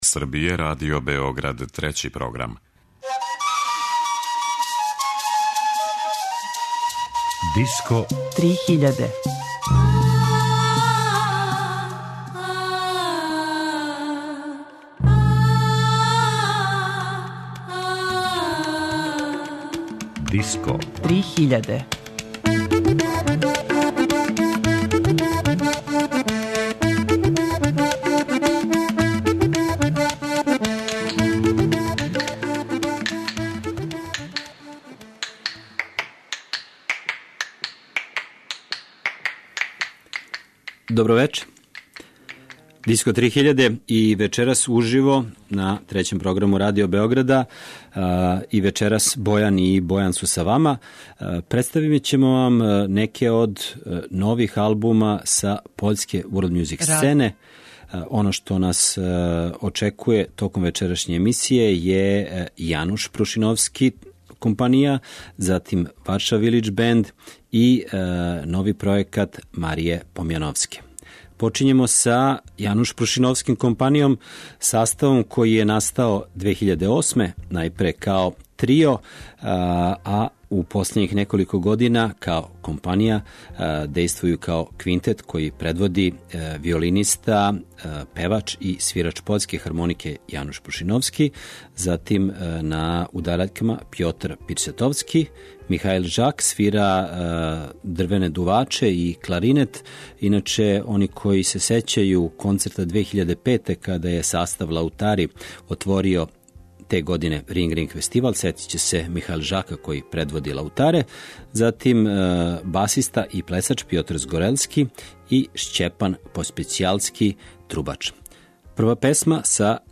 Нови албуми са world music сцене Пољске